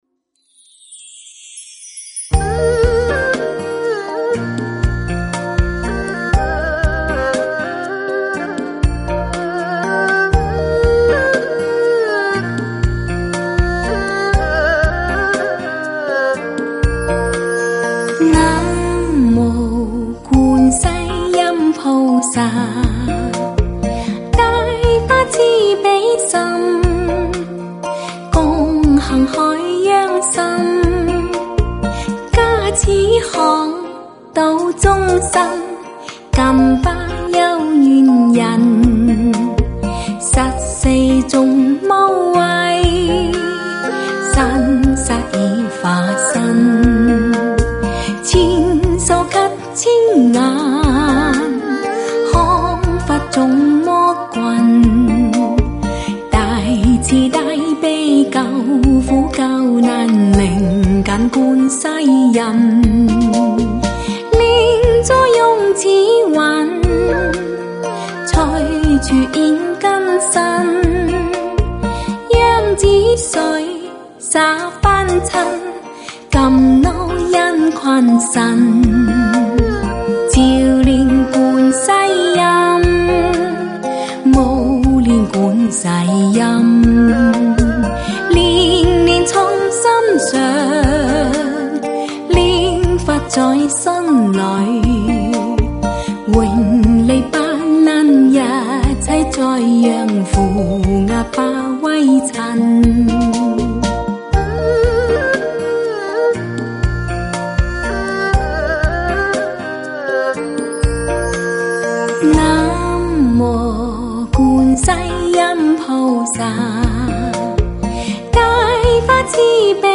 音乐类型：佛经唱颂音乐
无国界发烧女声虔诚唱诵。
低品质试听